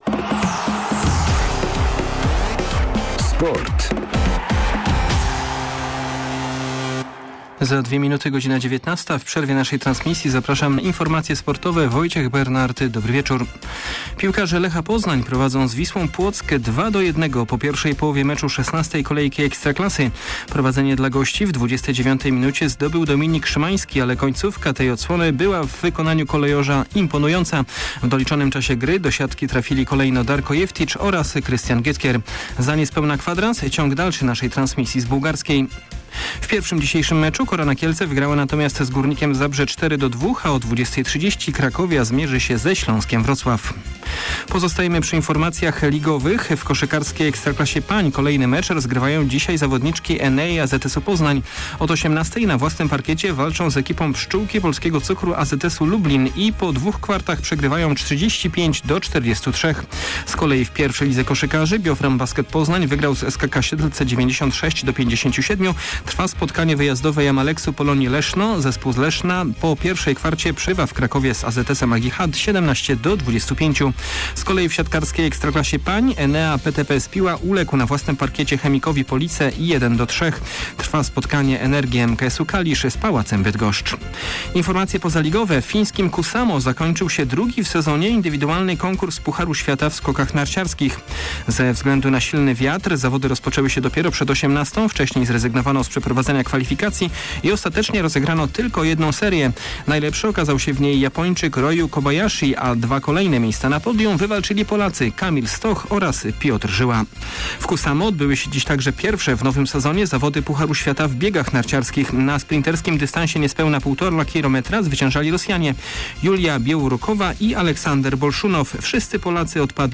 24.11. SERWIS SPORTOWY GODZ. 19:05
Nasz serwis tym razem w przerwie meczu Lecha Poznań. Poza informacjami z piłkarskich boisk, także spora porcja innych rezultatów ligowych oraz wyniki udanego dla Polaków konkursu Pucharu Świata w skokach narciarskich, który odbył się w Kuusamo. Na koniec zajrzymy na basen Term Maltańskich, gdzie krajowa czołówka pływaków walczy w zawodach Grand Prix Polski.